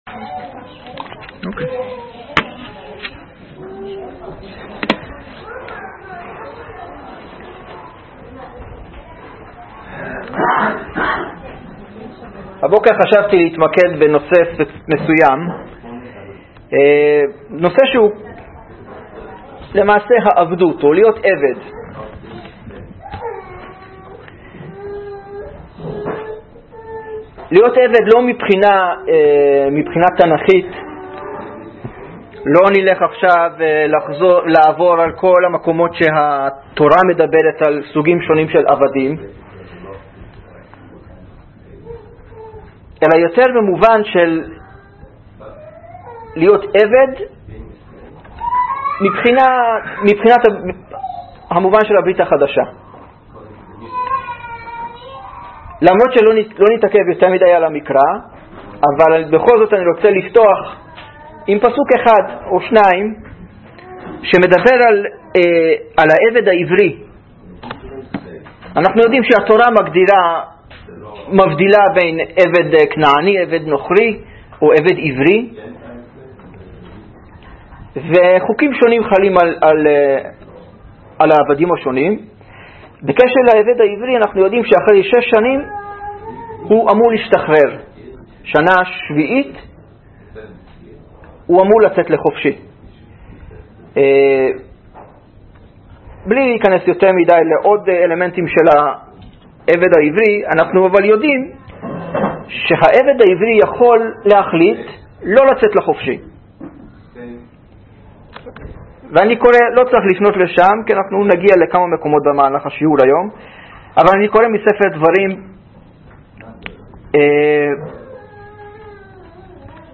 דצמבר 24, 2019 דרשות לפי נושאים